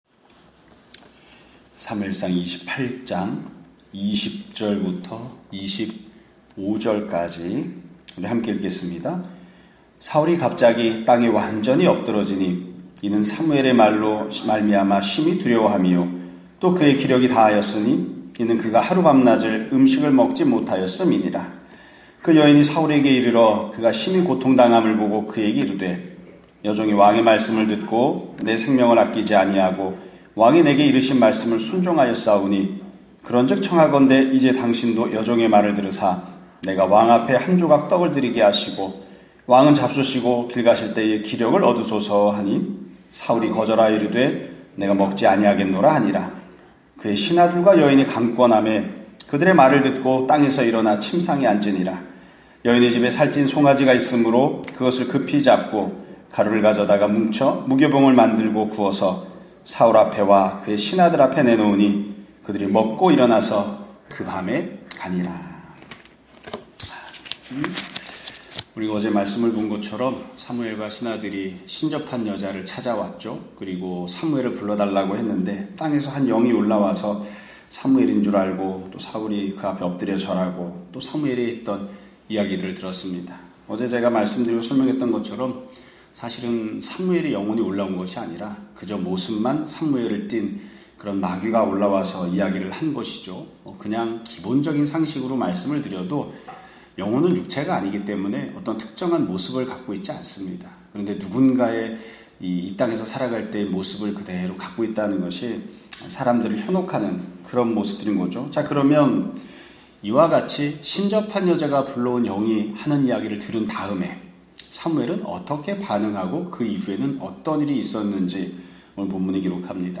2021년 12월 8일(수요일) <아침예배> 설교입니다.